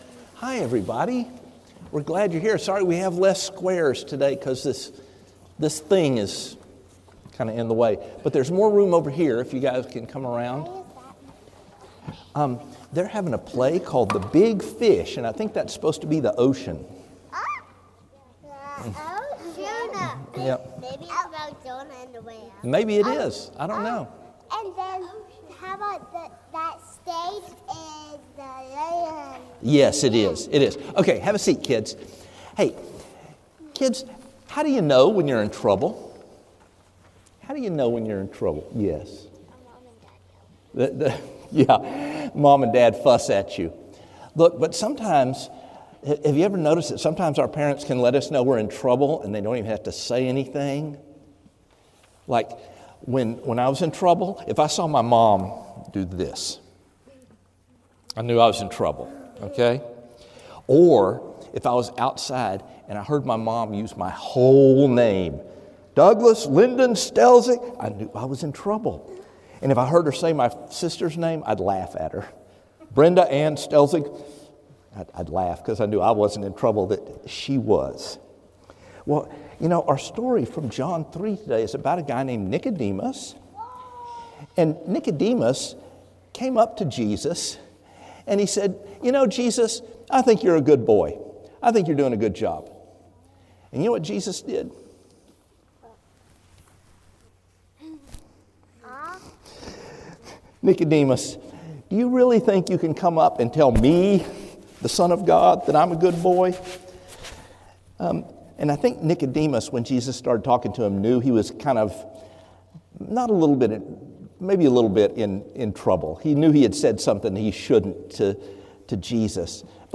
A message from the series "John: Who Is This?."